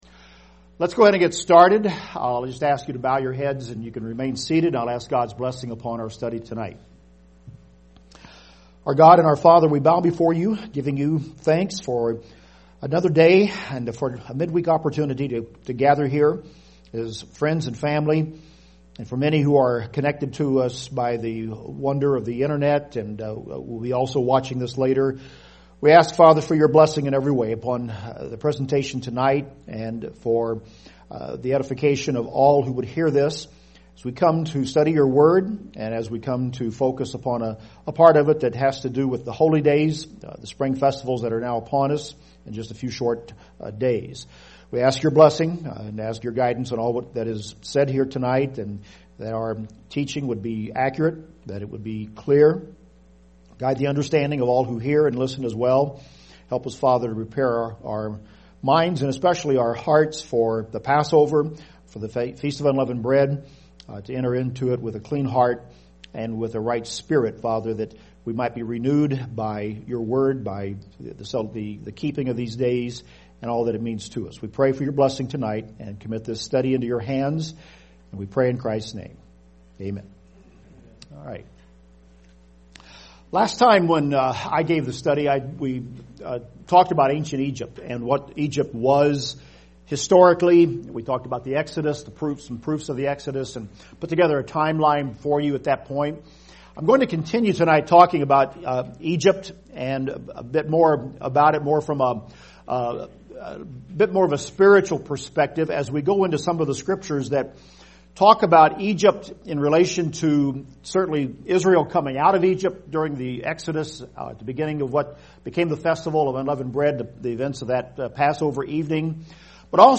This is part 5 in the Bible study series: Let Us Keep the Feasts. Why is Egypt a type of sin?